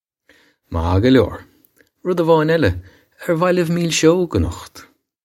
Pronunciation for how to say
Mah guh lyore. Rud a-voyne ella: air vah libh meel-shogue a-nukht?
This is an approximate phonetic pronunciation of the phrase.